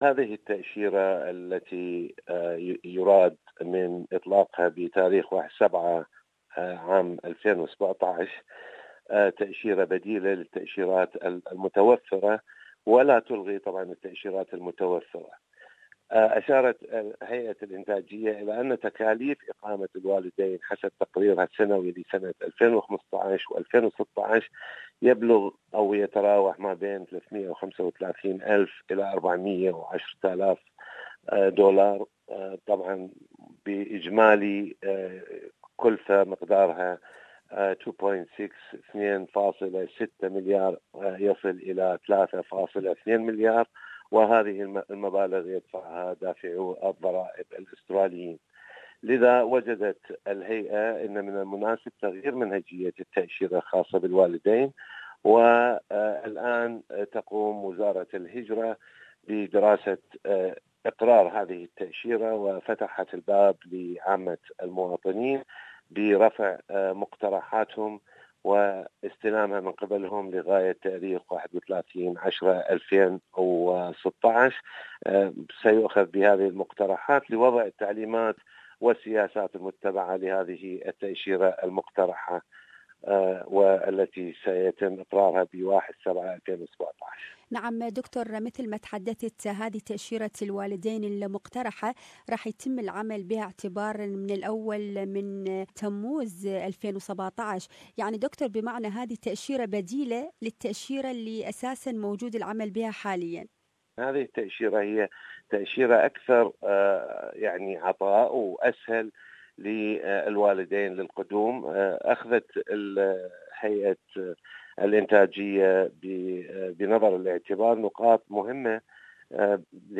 New Parents Visa. More about this issue, listen to this interview